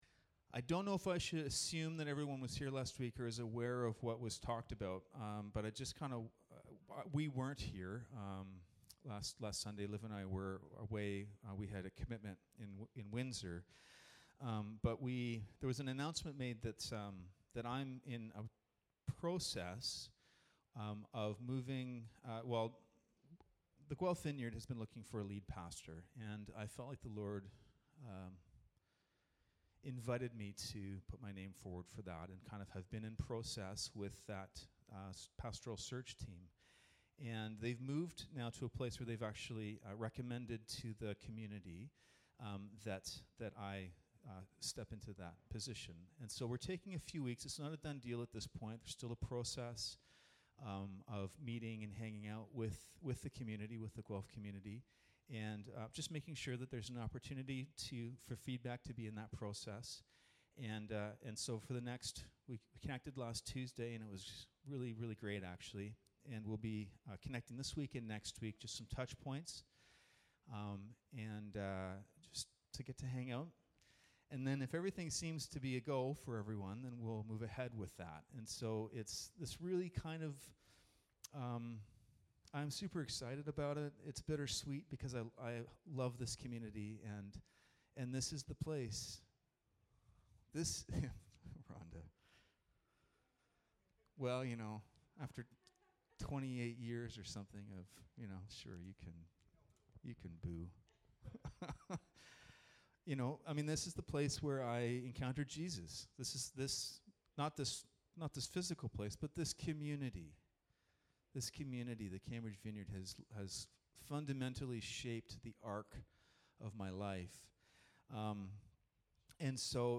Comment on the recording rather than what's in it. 2 Timothy 1:6-12 Service Type: Sunday Morning Bible Text